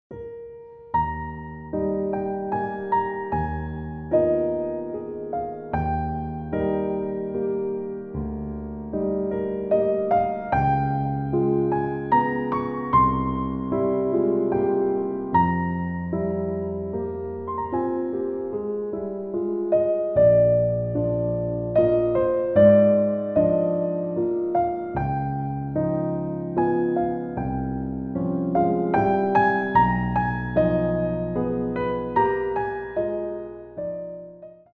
including original jazz, new age and meditation music.